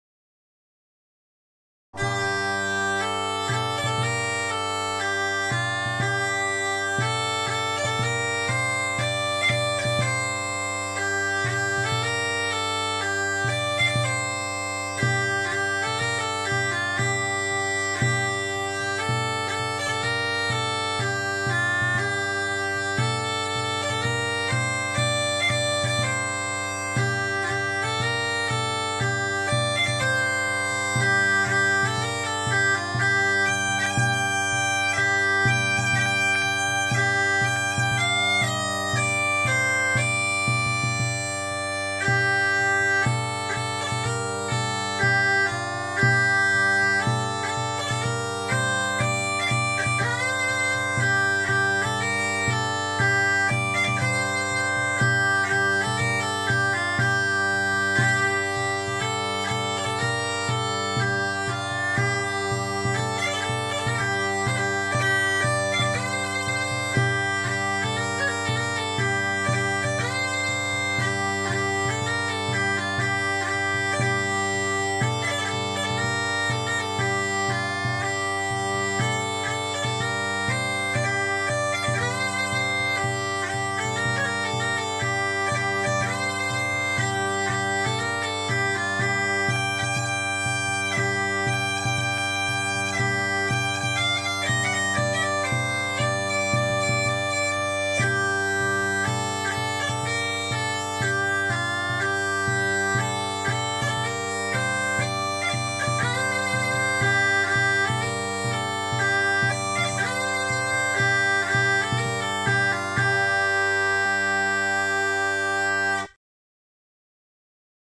Dudelsack